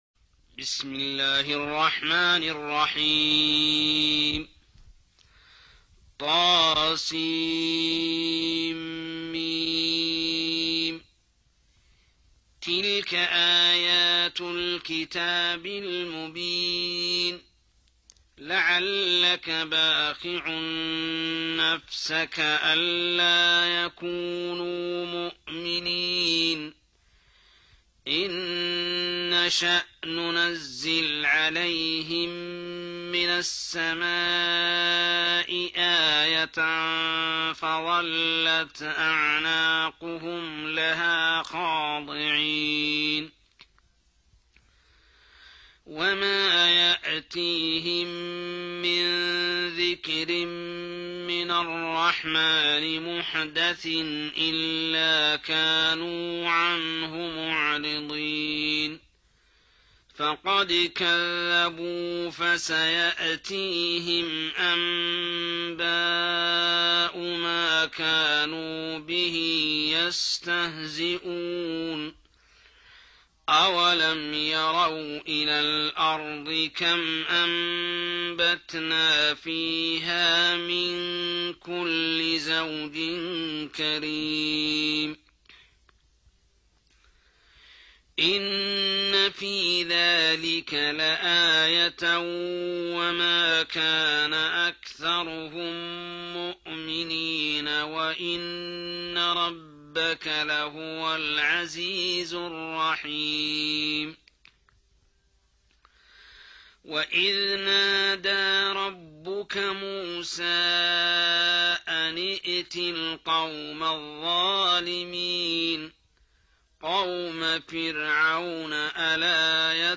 26. Surah Ash-Shu'ar�' سورة الشعراء Audio Quran Tarteel Recitation
Surah Sequence تتابع السورة Download Surah حمّل السورة Reciting Murattalah Audio for 26.